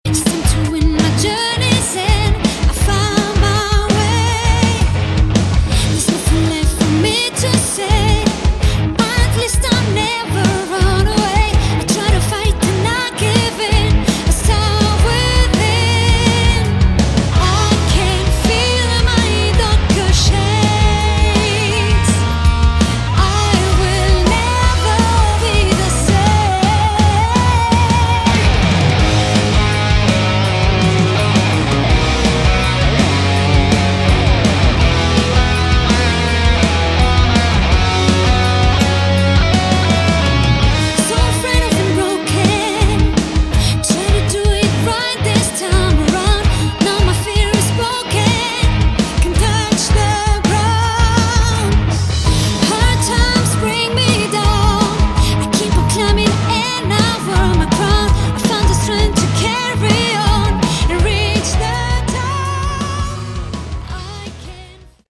Category: Melodic Rock
vocals
guitar
drums
bass